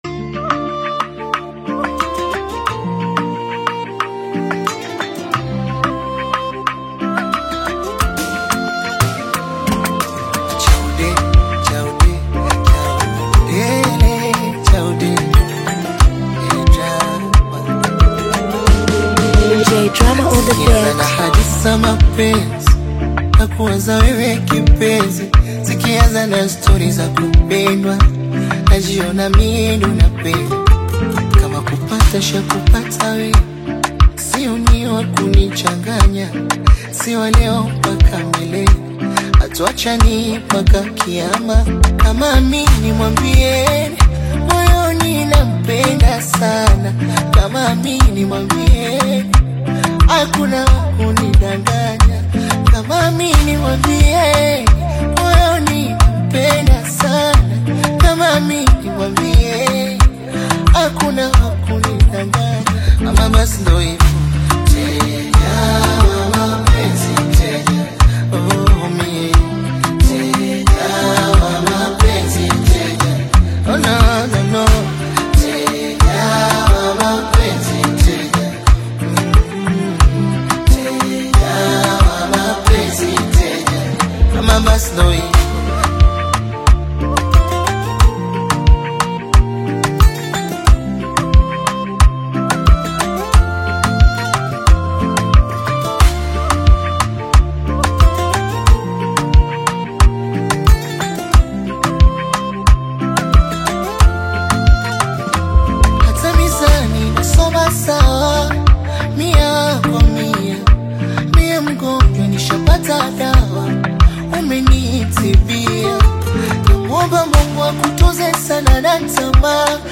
vibrant Afro-inspired single
Genre: Gospel